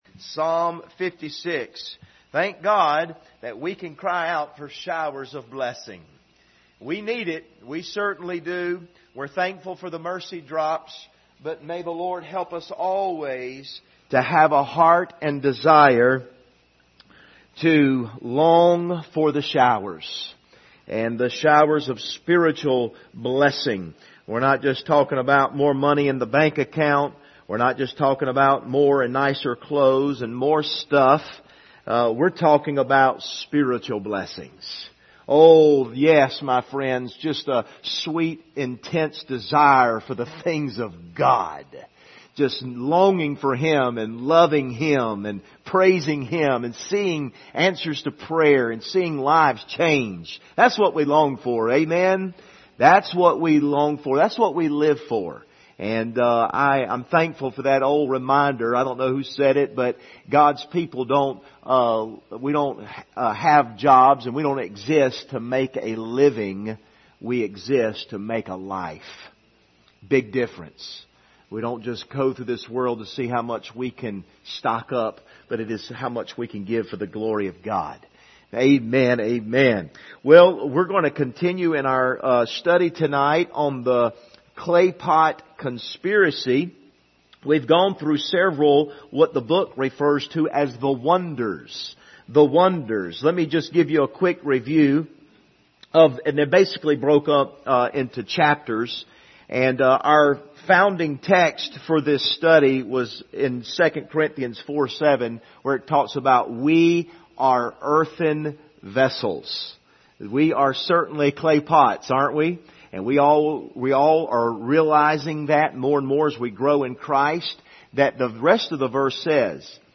Passage: Psalm 56:1-2 Service Type: Wednesday Evening Topics